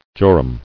[jo·rum]